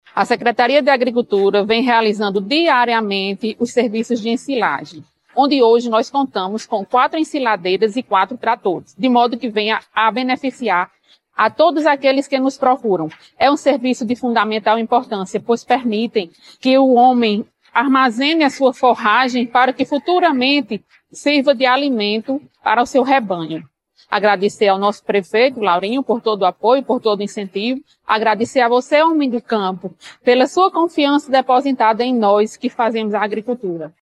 Em 2025, a estrutura foi ampliada e conta com 04 ensiladeiras e 04 tratores totalmente disponíveis para atender à demanda das comunidades beneficiadas. É o que destaca a secretária municipal de agricultura Claudinete Costa.
ÁUDIO SEC. AGRICULTURA – CLAUDINETE COSTA – ENSILAGEM